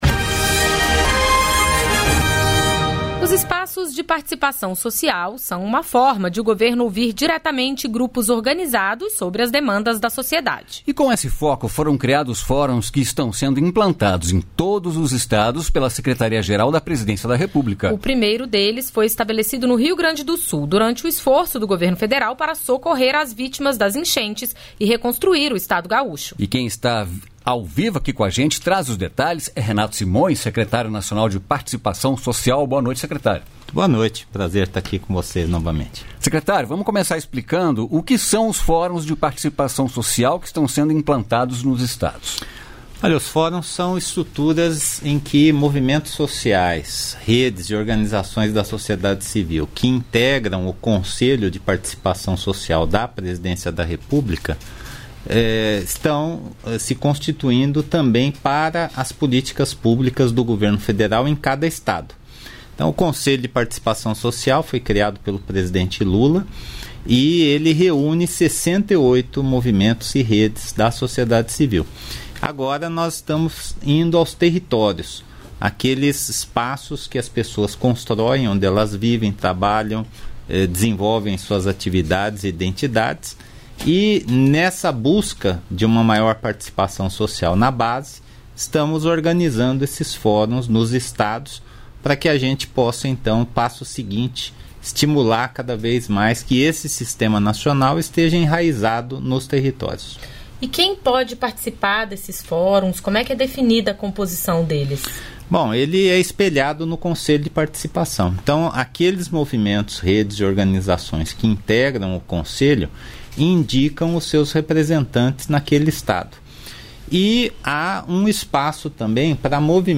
Entrevistas da Voz Paulo Teixeira, ministro do Desenvolvimento Agrário e Agricultura Familiar Para ajudar o brasileiro a pagar suas dívidas, o governo lançou o Desenrola Brasil.